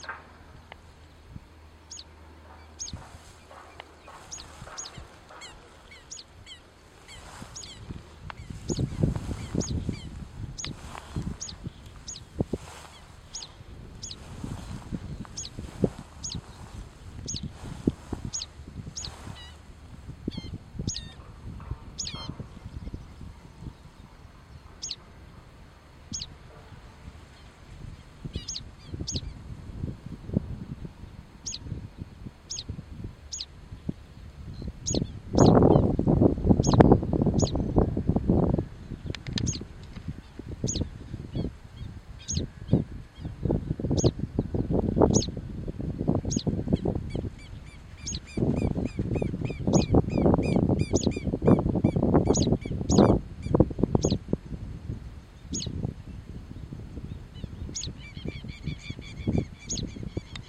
Yellowish Pipit (Anthus chii)
Province / Department: Santiago del Estero
Condition: Wild
Certainty: Recorded vocal